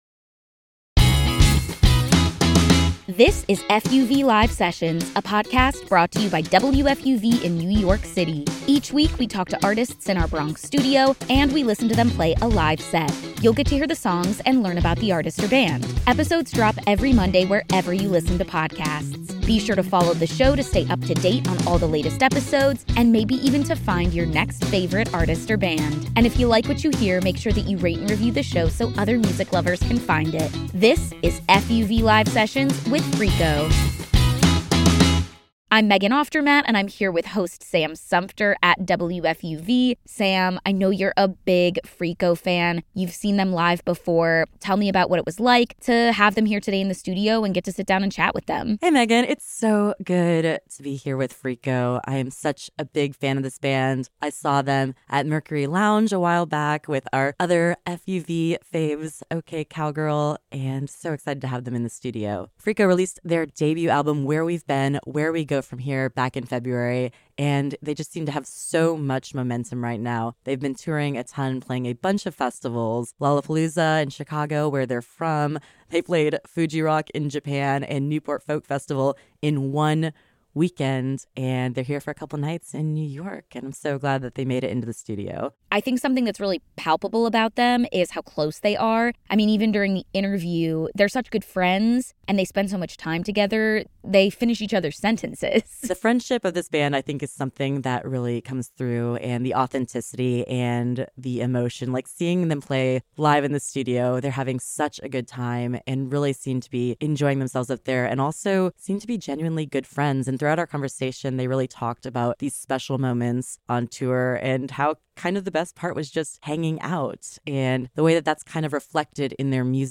the Chicago-based band, stopped by Studio A
Indie Rock